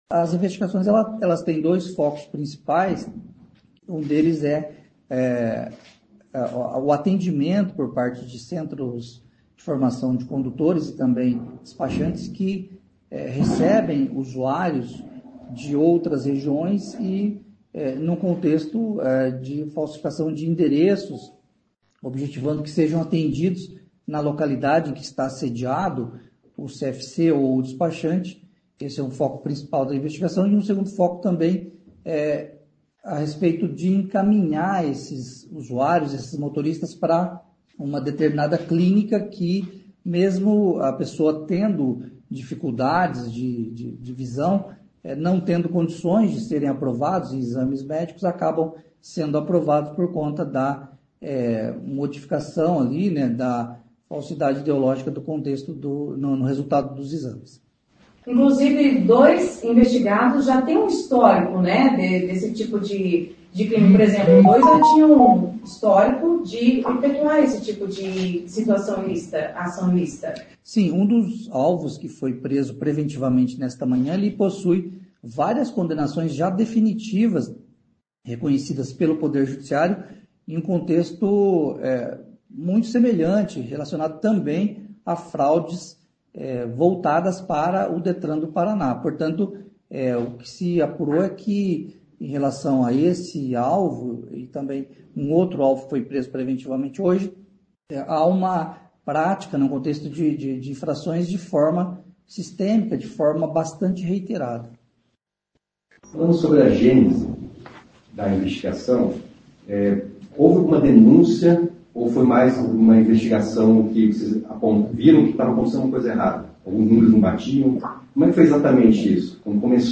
O promotor de Justiça Marcelo Alessandro Gobbato deu mais detalhes em entrevista coletiva nesta manhã: